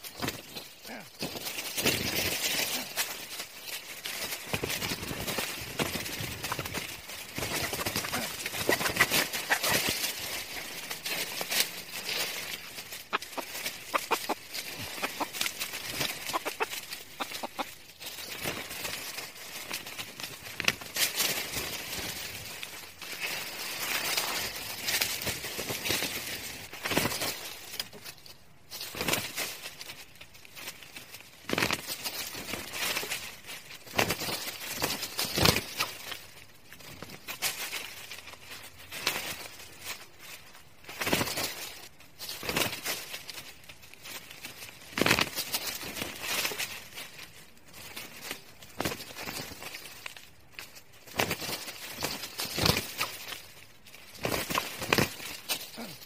Tiếng Gà Đá nhau, Chọi nhau, Đánh nhau…
Tiếng Gà Chọi Đá nhau, Tung đòn, Lên đòn… Tiếng Máy Test, check, kiểm tra… đồ thật giả
Thể loại: Tiếng chim
tieng-ga-da-nhau-choi-nhau-danh-nhau-www_tiengdong_com.mp3